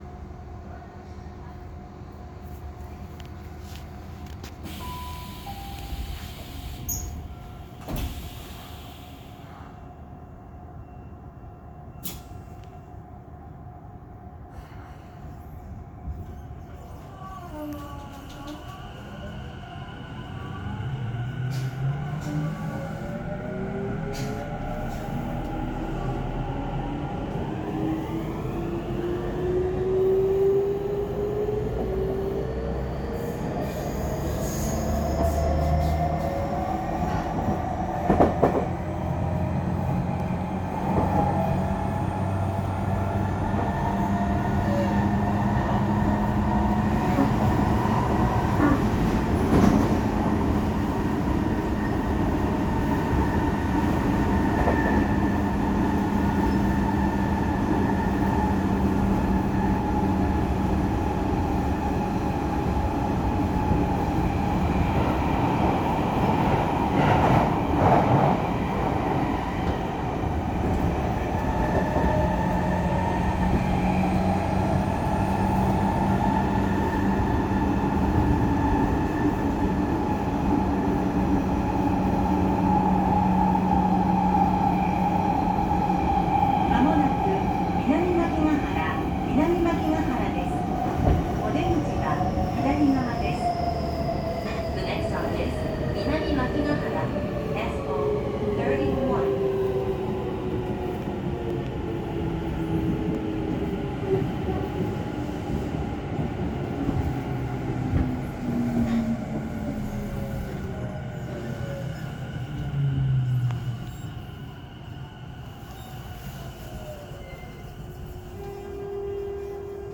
・5050系（後期車）走行音
【相鉄いずみ野線】緑園都市→南万騎が原
5000系と同じ日立IGBTの編成が多いものの、後期に製造された分（８連の一部と4000番台全て）は起動時の音の響き方が大分変わっており、東武50000系統と全く同じ音になりました（ブレーキ解除音は違うけれど）。